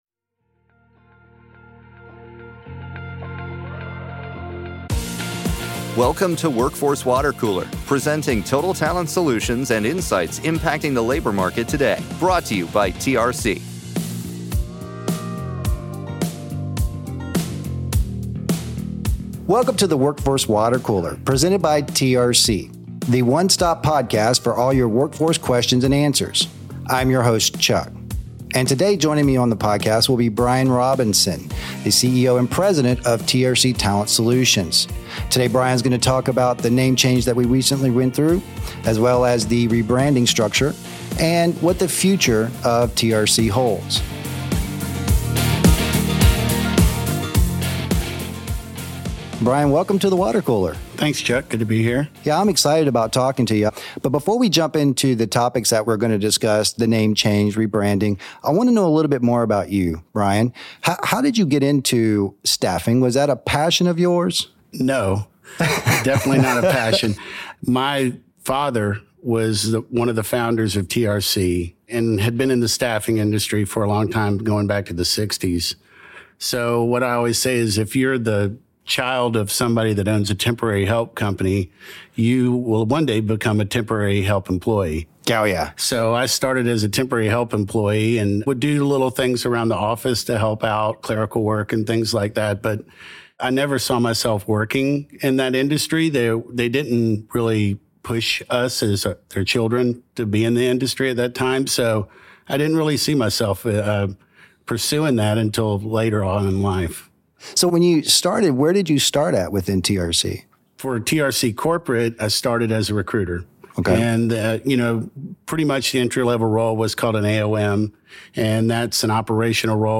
for an insightful conversation about his journey in the staffing industry, and vision for its future!